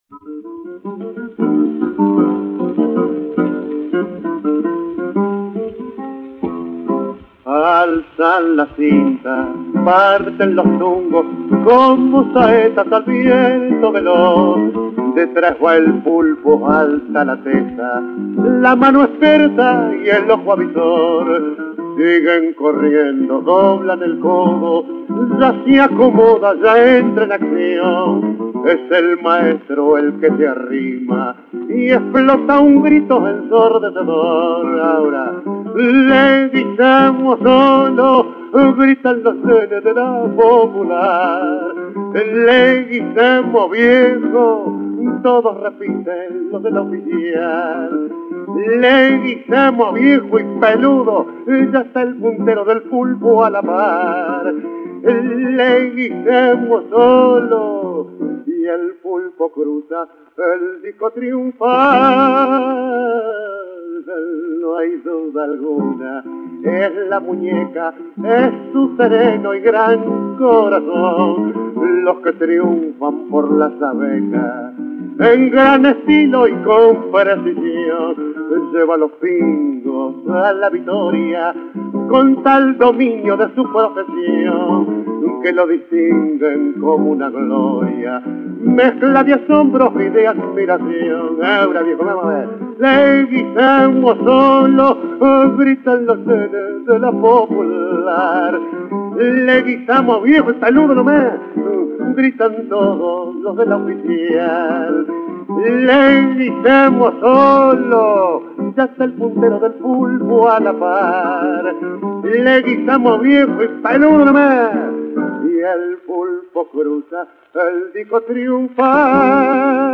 Танго